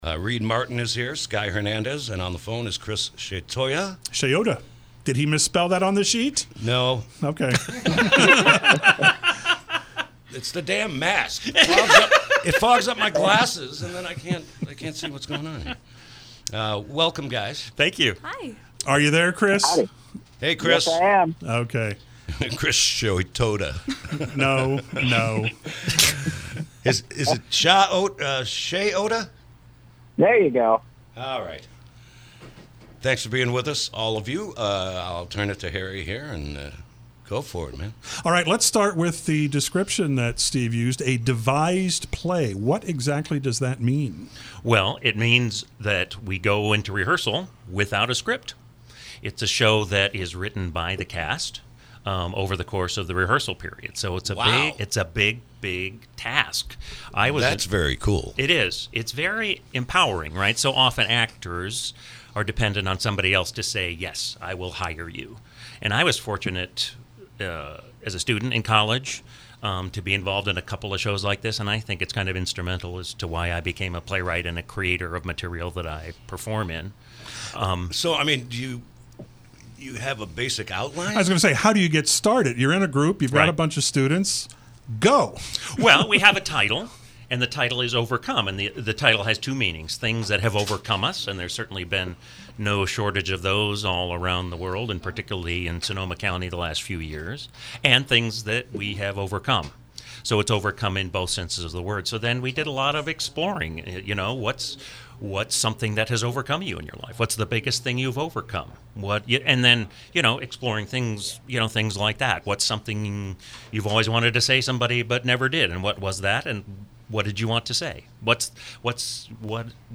KSRO Interview – “overcome, a devised play”